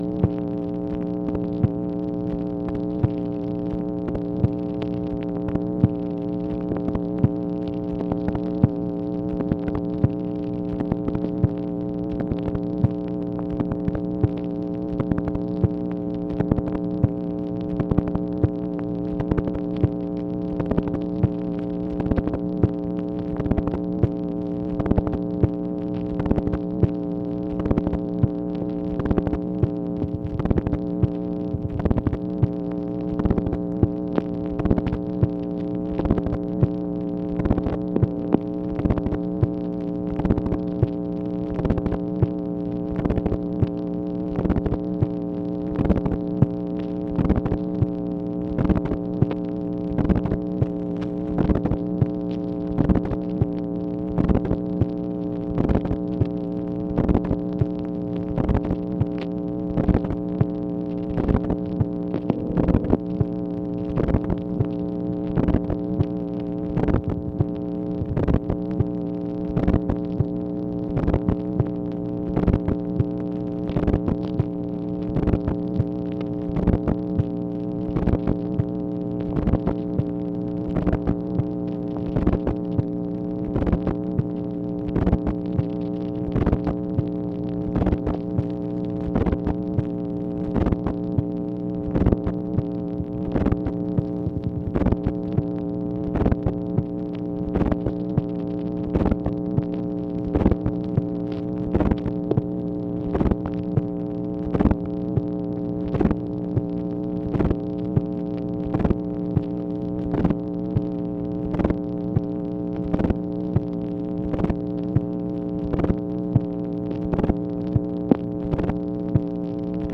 MACHINE NOISE, September 4, 1964
Secret White House Tapes | Lyndon B. Johnson Presidency